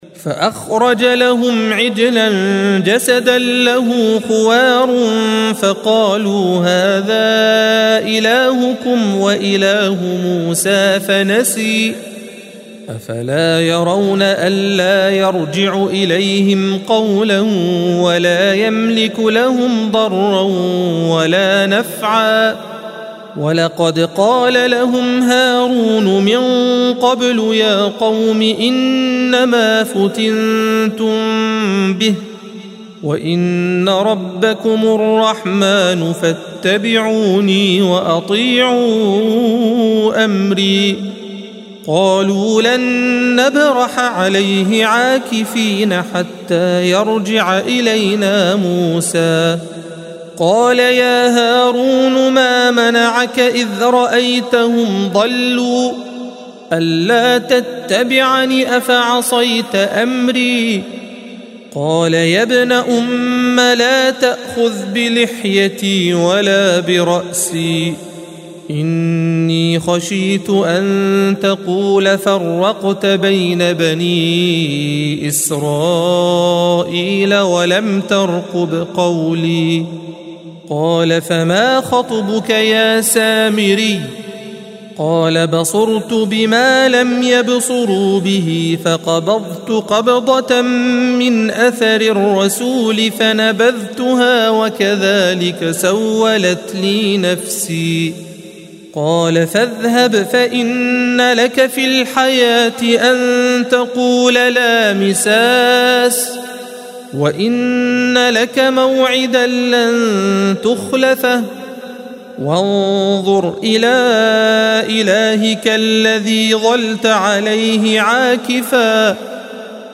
الصفحة 318 - القارئ